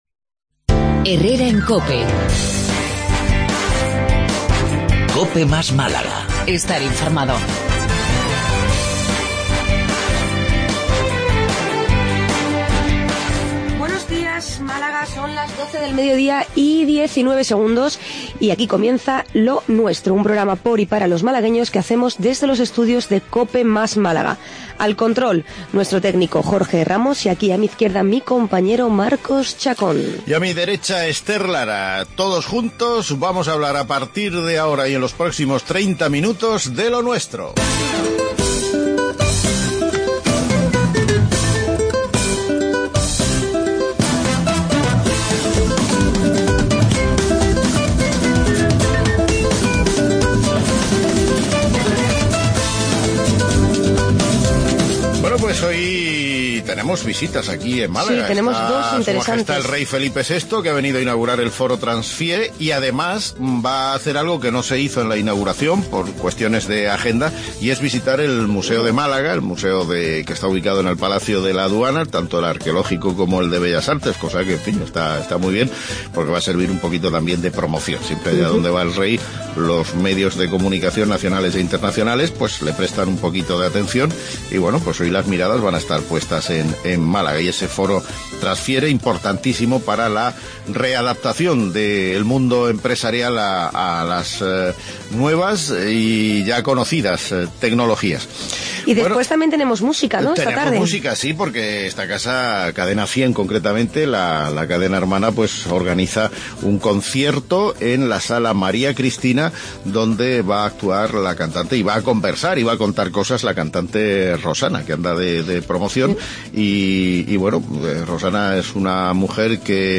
AUDIO: Magazine diario